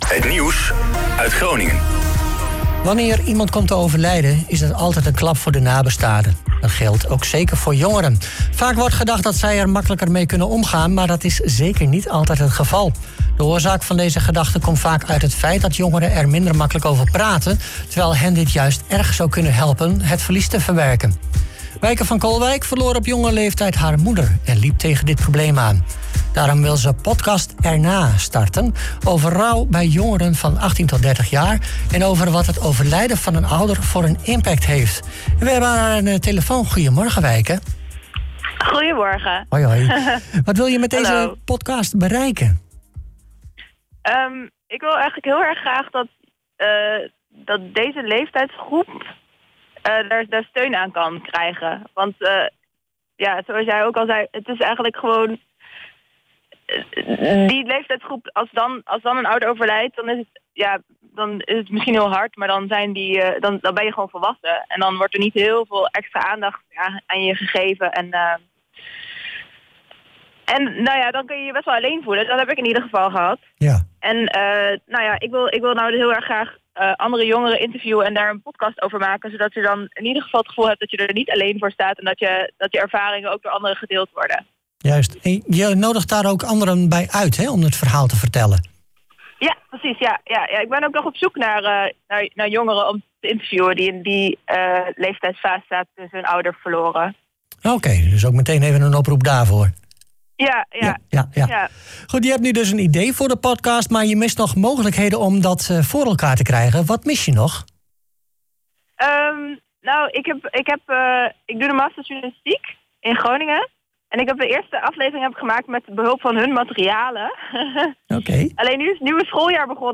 zo vertelde ze woensdagochtend in de OOG Ochtendshow. Beluister het interview hier terug: